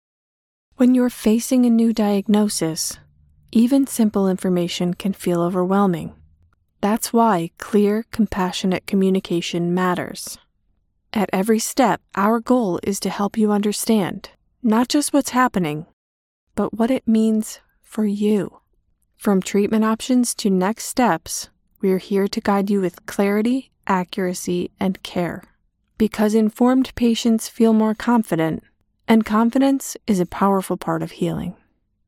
Professional Voice, Personality Included - Female Voice actor with chill vibes, a clear voice with adaptability for your project!
Medical Explainer Clear concise patient education material
Middle Aged